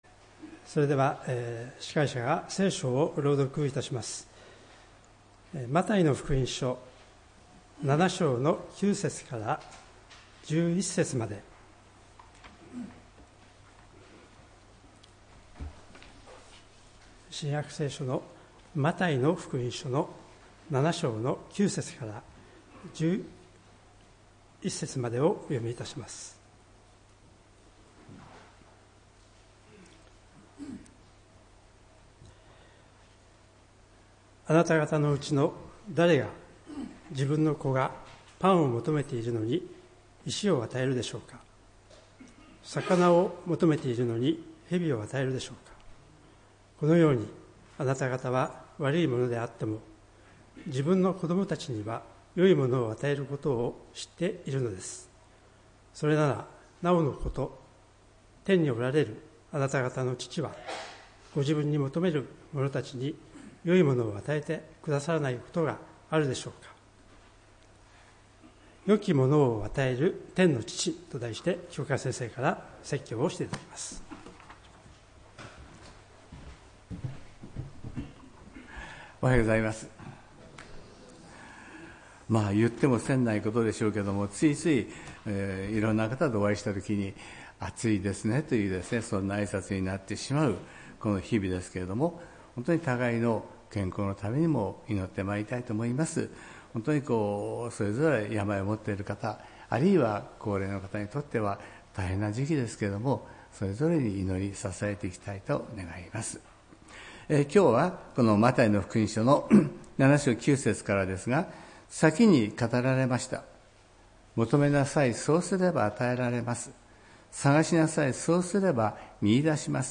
礼拝メッセージ「良きものを与える天の父」(７月６日）